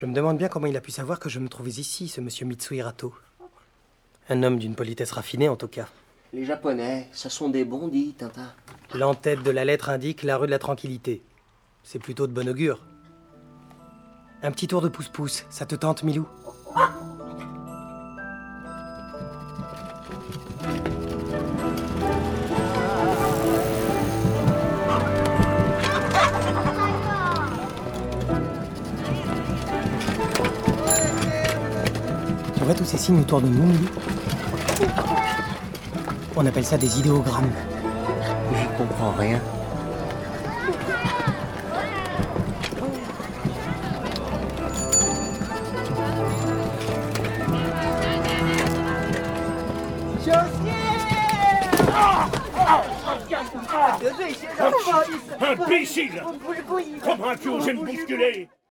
Tintin en feuilleton radiophonique sur France Culture
La performance est réalisée par des acteurs de la Comédie-Française, en partenariat avec l’Orchestre National de France.